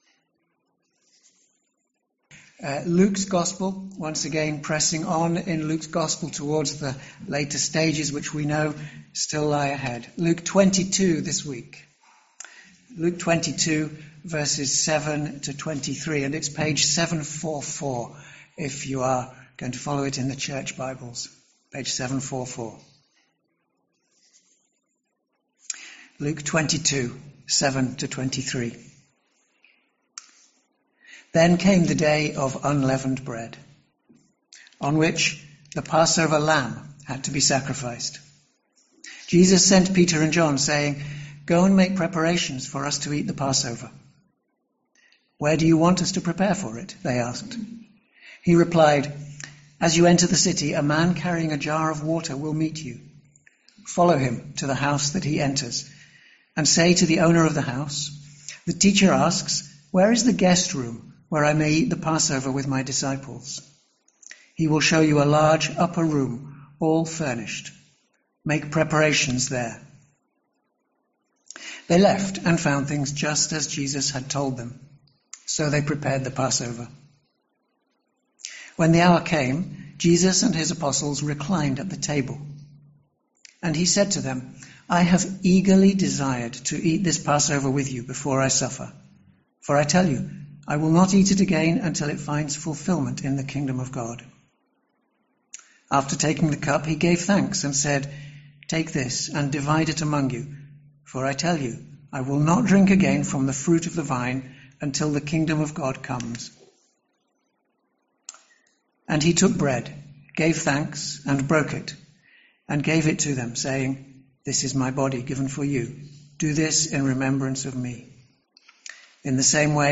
Sermons - Swanfield Chapel